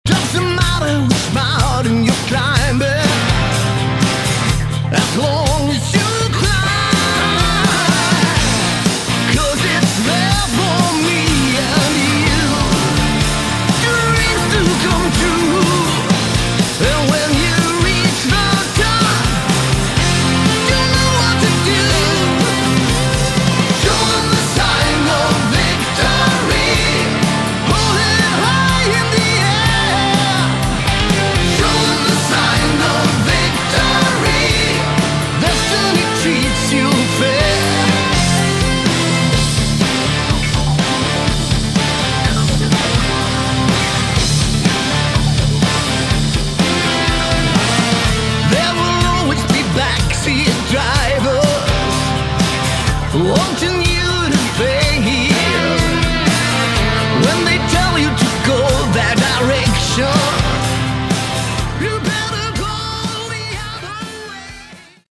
Category: Hard Rock
lead vocals
keyboards
bass
lead guitar
drums